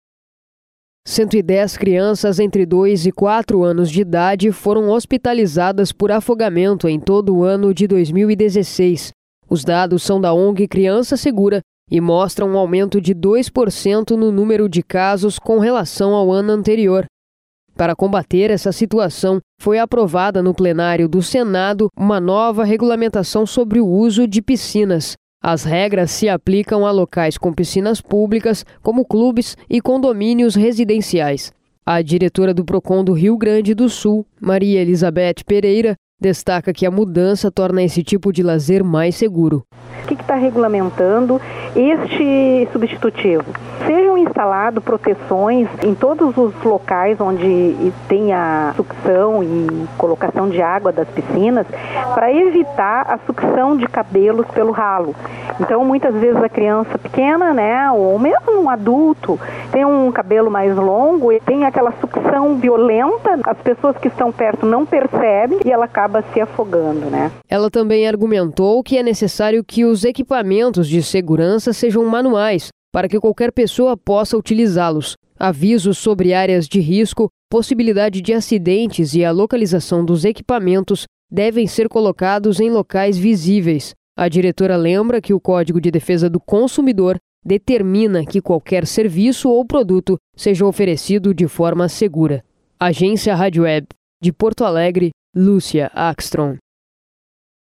Entrevista concedida à Agência Radio Web